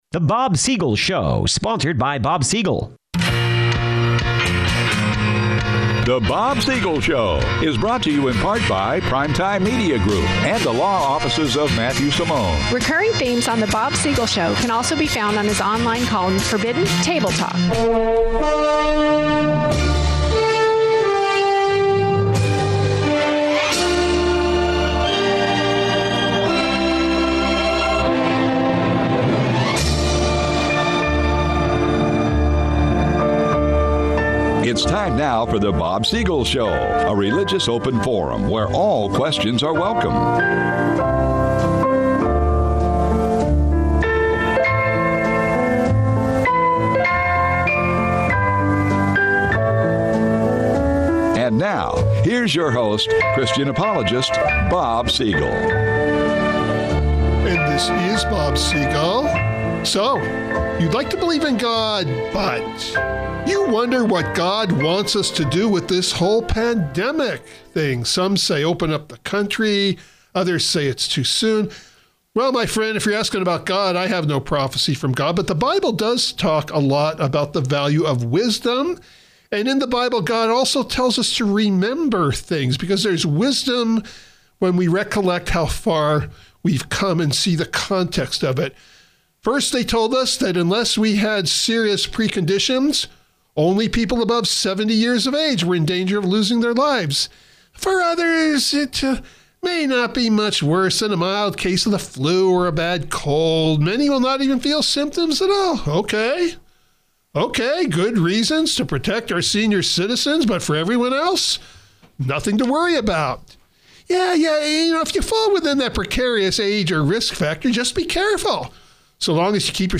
This special program with limited commercial interruptions has two distinct but related sections.